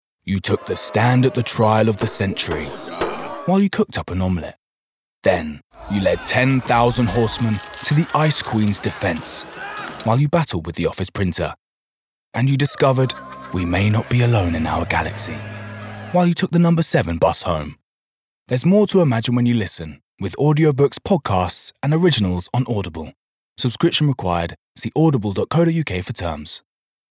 London
Male
Cool
Fresh
Smooth